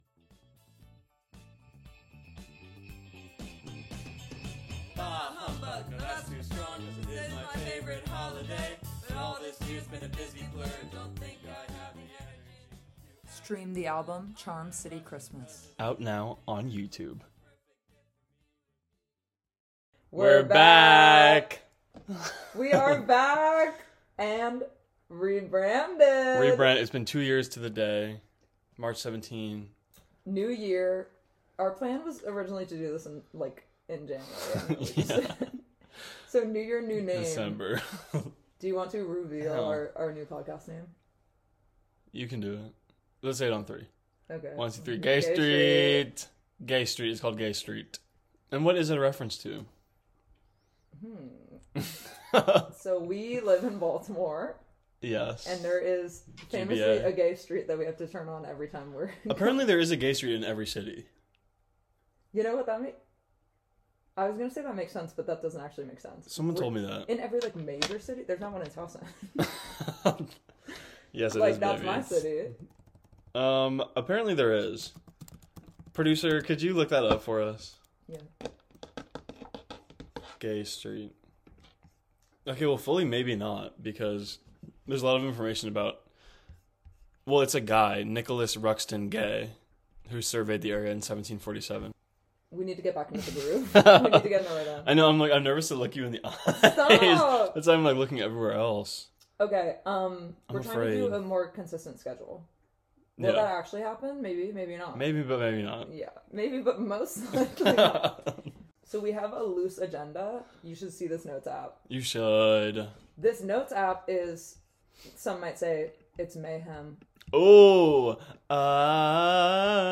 Genres: Comedy , Comedy Interviews , Improv